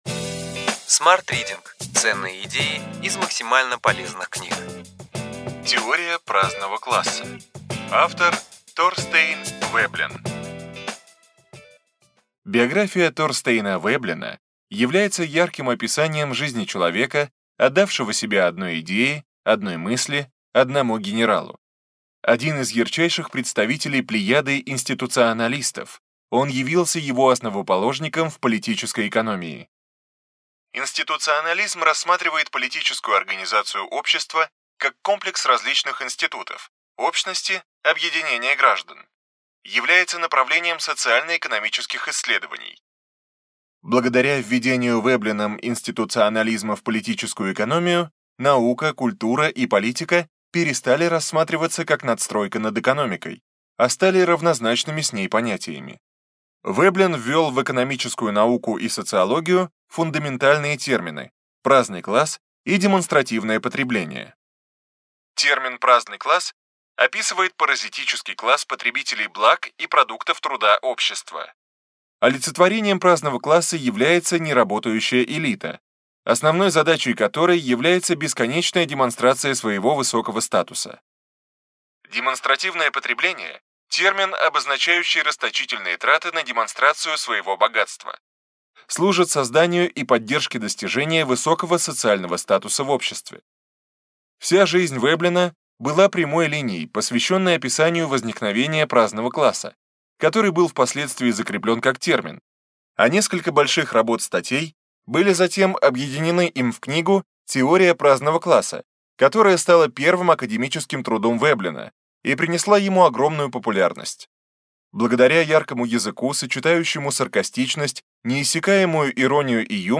Аудиокнига Ключевые идеи книги: Теория праздного класса. Торстейн Веблен | Библиотека аудиокниг